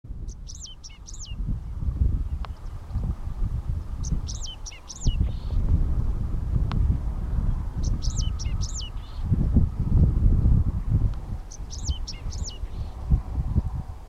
Caminheiro-de-espora (Anthus correndera)
Fase da vida: Adulto
Detalhada localização: Entre Trelew y Gaiman
Condição: Selvagem
Certeza: Observado, Gravado Vocal
Cachirla-Comun.mp3